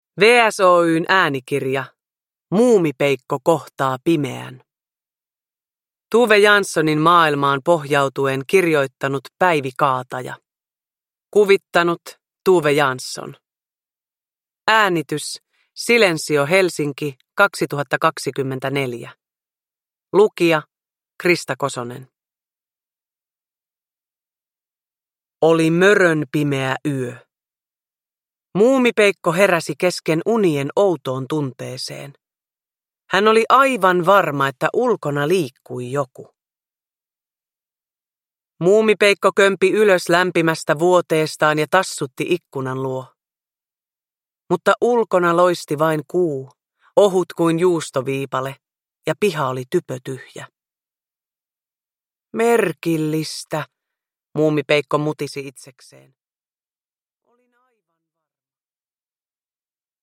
Muumipeikko kohtaa pimeän – Ljudbok
Uppläsare: Krista Kosonen